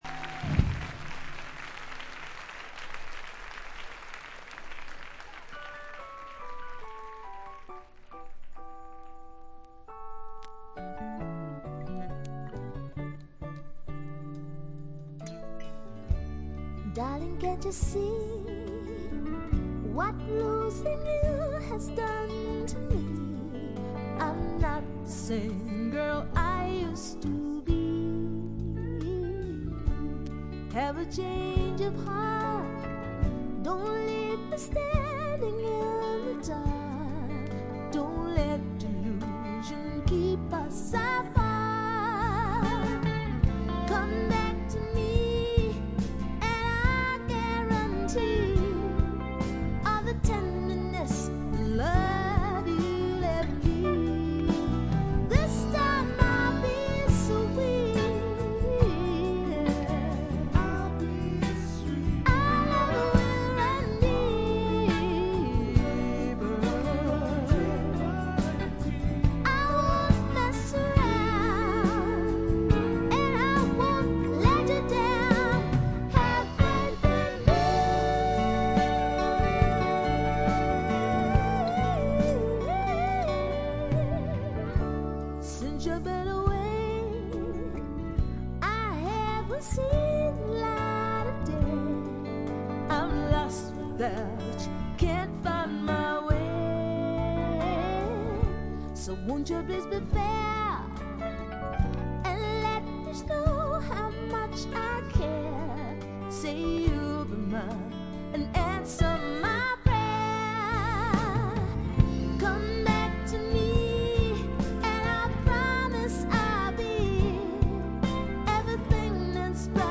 95年10月20、21日に渋谷On Air Eastで行われたライブをほぼステージに忠実に再現した2枚組です。
試聴曲は現品からの取り込み音源です。
Vocals, Acoustic Guitar, Percussion